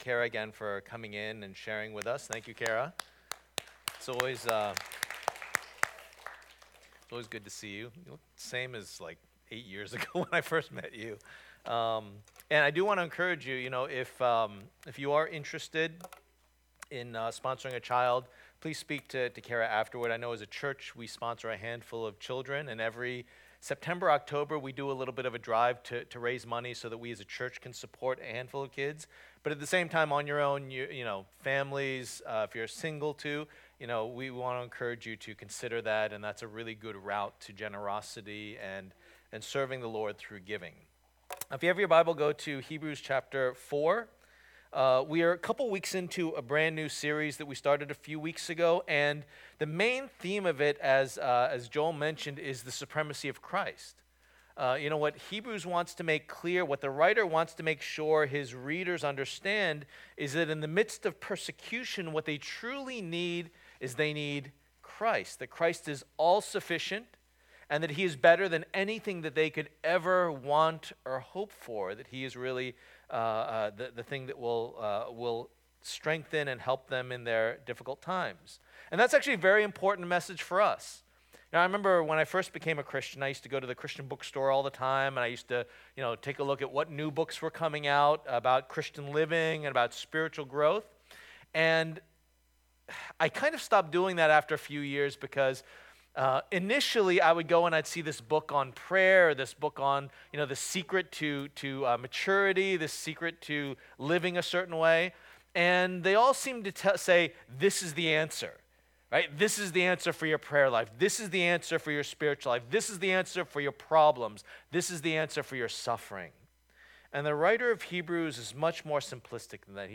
Passage: Hebrews 4:14-5:10 Service Type: Lord's Day